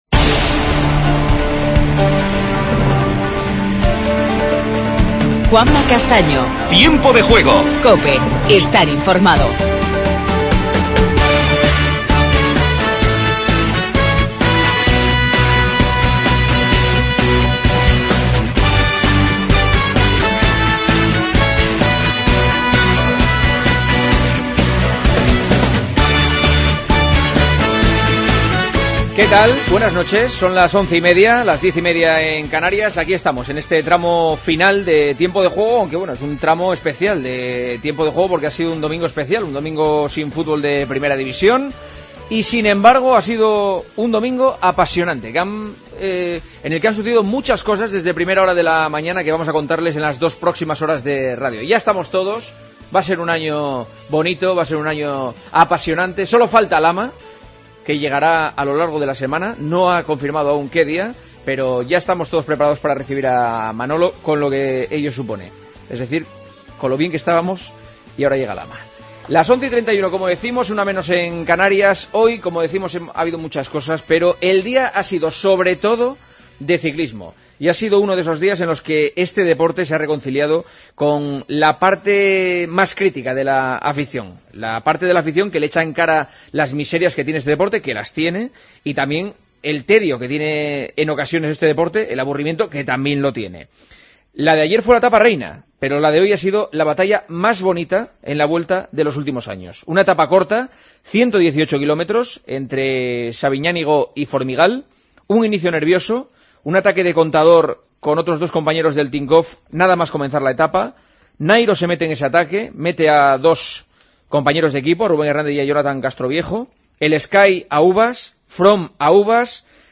Redacción digital Madrid - Publicado el 05 sep 2016, 00:35 - Actualizado 14 mar 2023, 08:07 1 min lectura Descargar Facebook Twitter Whatsapp Telegram Enviar por email Copiar enlace Titulares del día. Nairo da un golpe de efecto en la Vuelta y Contador se acerca al podio.
Hablamos con Alberto Contador.